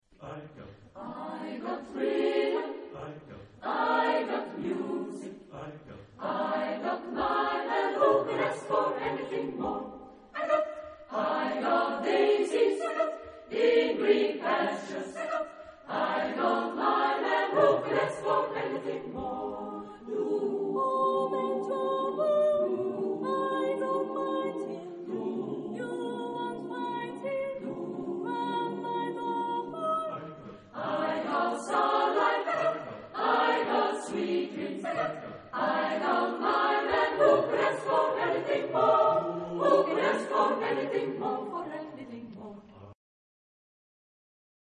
Genre-Style-Form: Partsong
Mood of the piece: chromatism ; expressive ; slow
Type of Choir: SATB  (4 mixed voices )
Tonality: D minor ; A minor